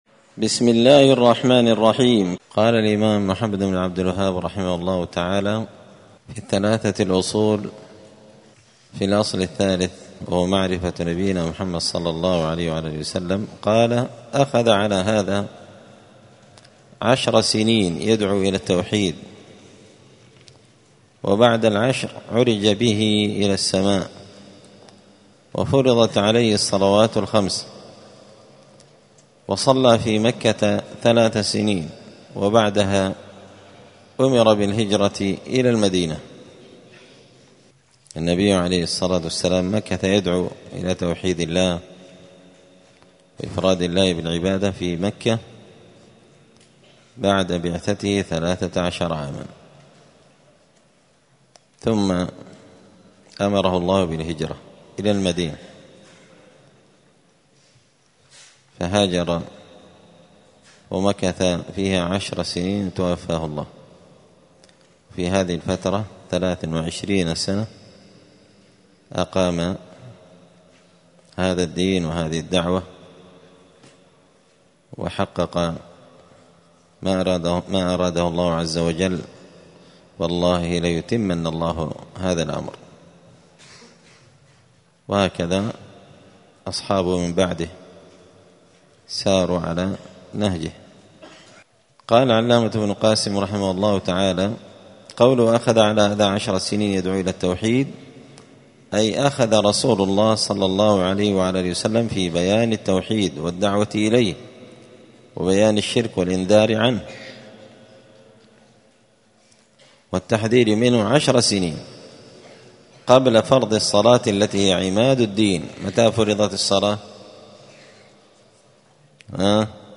دار الحديث السلفية بمسجد الفرقان بقشن المهرة اليمن
*الدرس الرابع والثلاثون (34) من قوله {أخذ على هذا عشر سنين يدعو إلى التوحيد وبعد العشر عرج به إلى السماء…}*